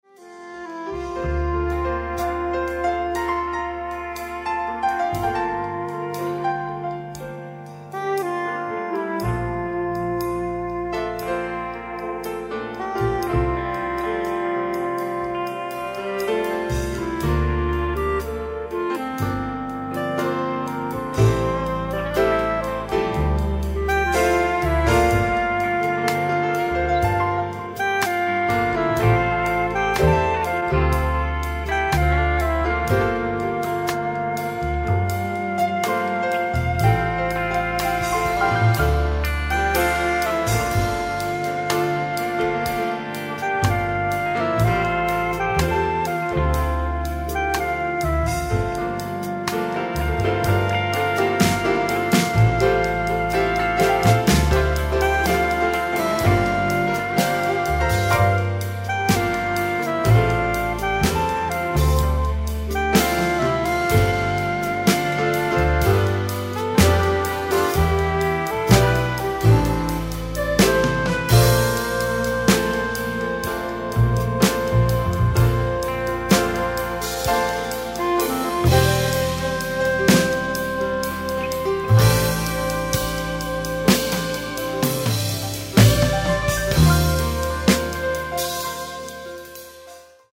パワー・ステーション・スタジオ、ニューヨーク・シティー 1985
※試聴用に実際より音質を落としています。